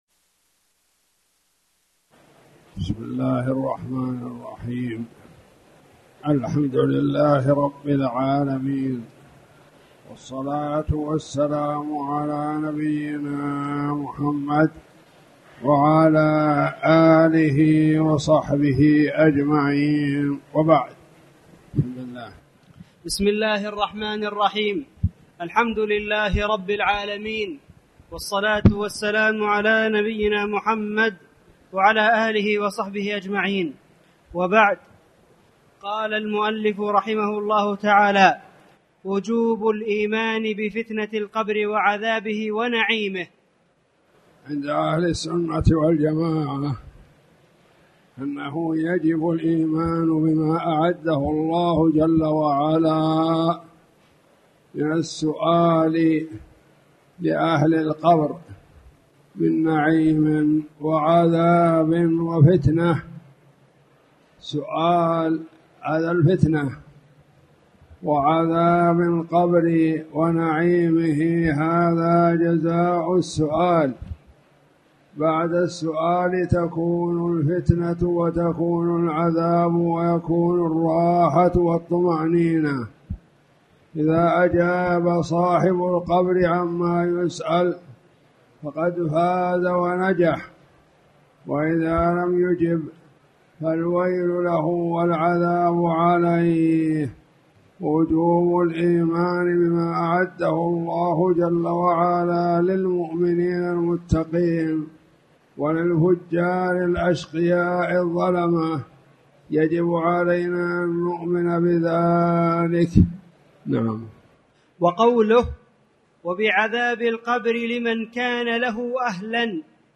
تاريخ النشر ١٦ ذو القعدة ١٤٣٨ هـ المكان: المسجد الحرام الشيخ